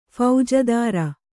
♪ phaujadāra